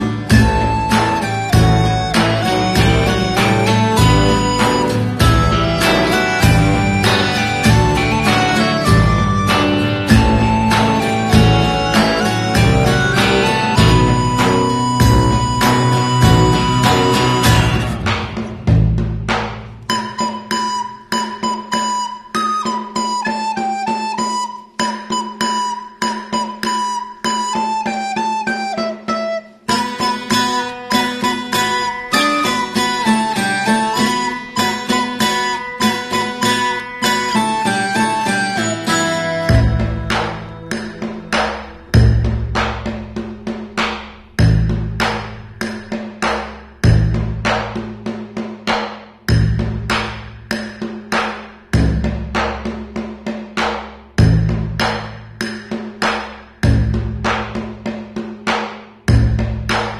Kalimba Tutorial